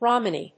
音節Rom・a・ny 発音記号・読み方
/rάməni(米国英語), rˈɔməni(英国英語)/